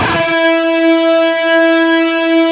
LEAD E 3 LP.wav